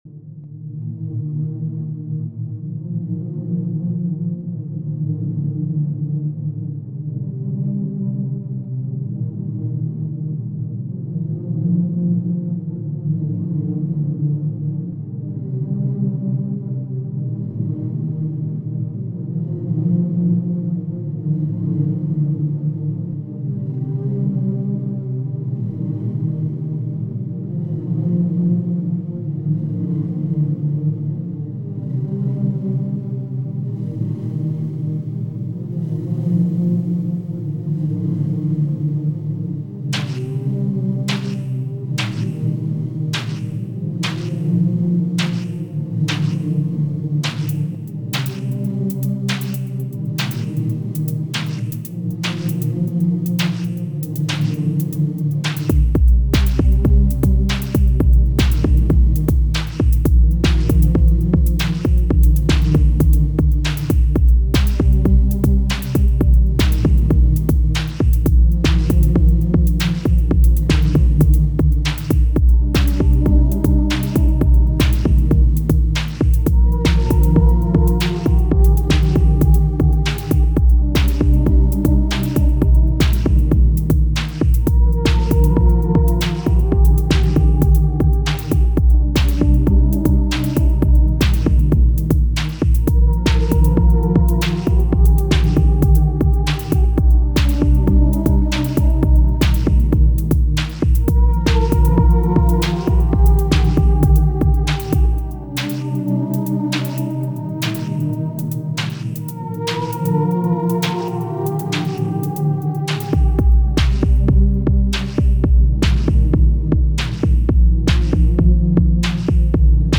I had forgotten that to me Digitone is mostly a “melancholy” machine, it’s a perfect autumn companion :slight_smile: